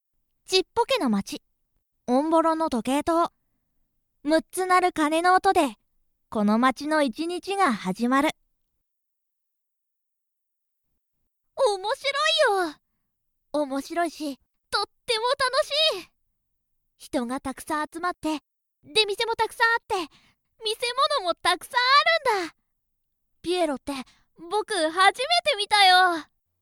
メインキャラクター・サブキャラクターの、イラスト＆簡単な紹介＆サンプルボイスです。